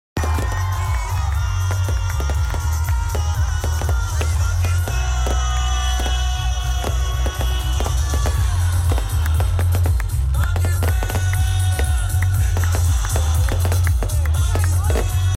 Fireworks in Mehria Paradise, Attock